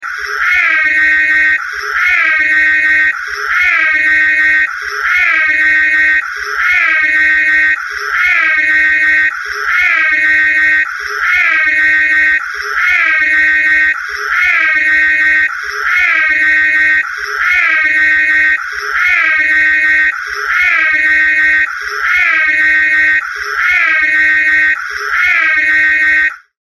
Category: Siren Sounds